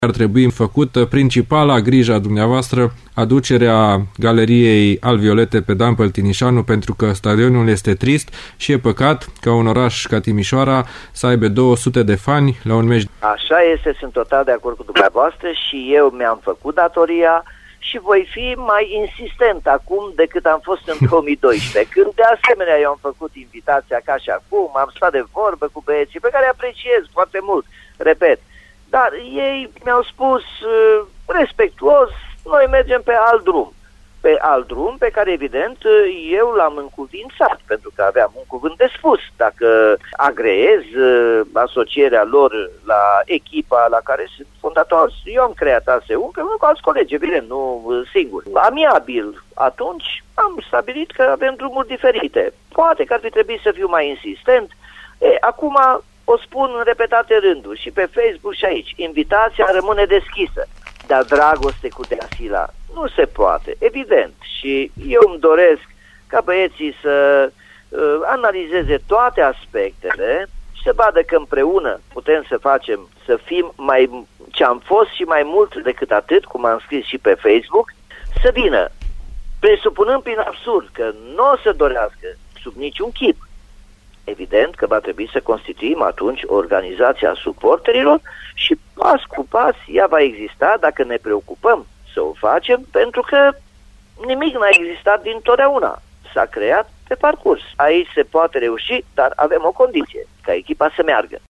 Invitat în ediția de astăzi a emisiunii Arena Radio, primarul Nicolae Robu a sintetizat discuția purtată la mijlocul săptămânii cu liderii Druckeria despre o eventuală revenire a fanilor din Peluza Sud pe stadionul „Dan Păltinișanu”.